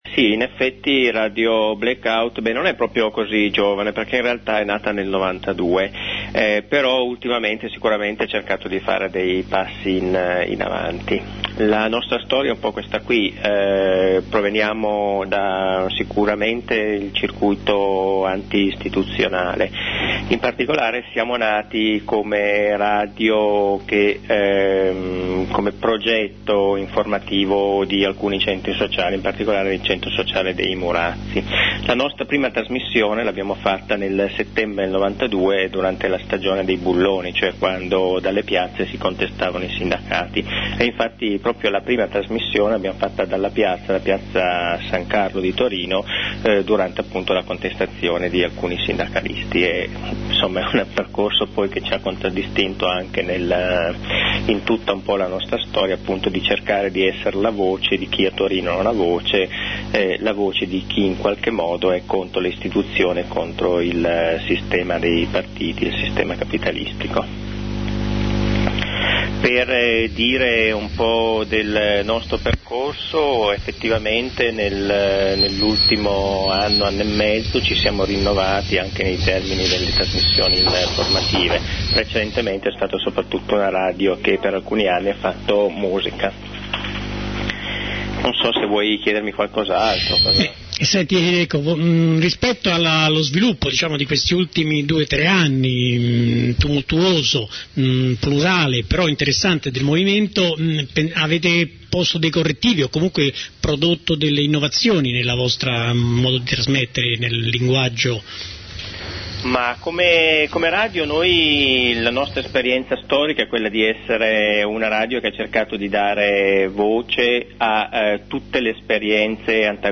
da una trasmissione realizzata da Radio Gap sabato 10 maggio.
Intervista a Radio Black Out